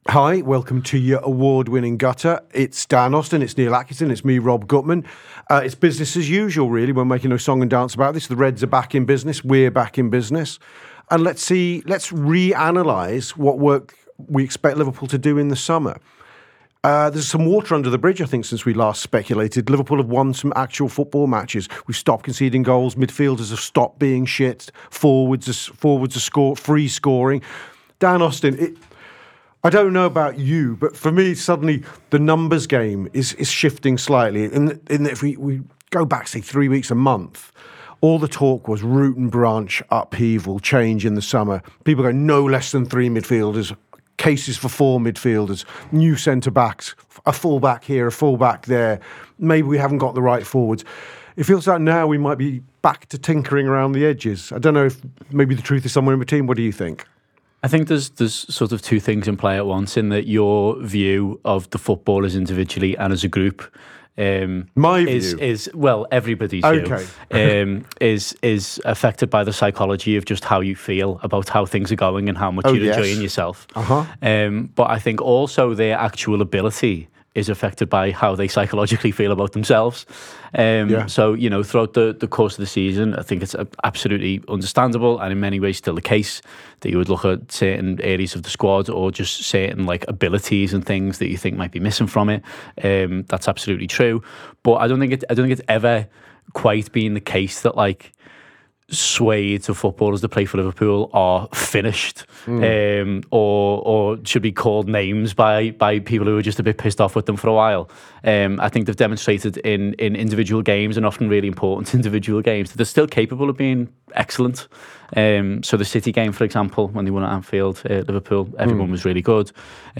Below is a clip from the show – subscribe for more on Jude Bellingham, Mason Mount and more…